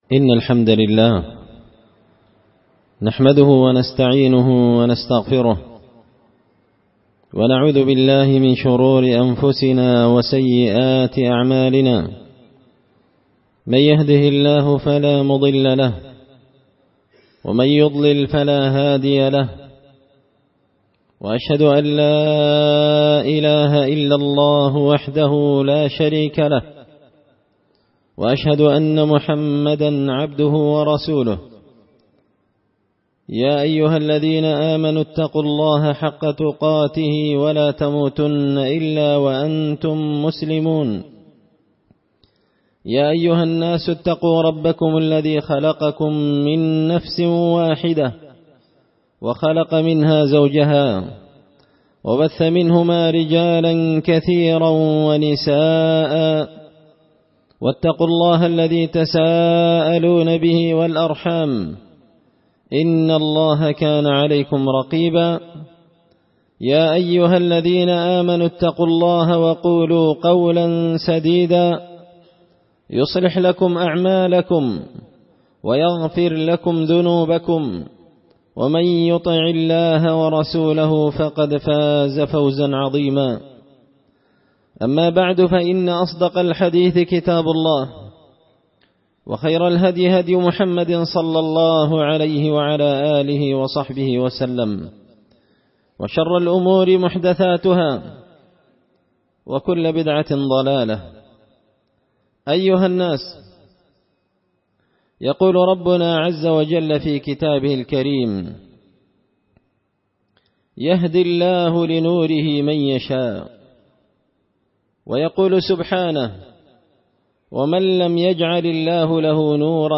خطبة جمعة بعنوان – ومن يجعل الله له نورا فما له من نور
دار الحديث بمسجد الفرقان ـ قشن ـ المهرة ـ اليمن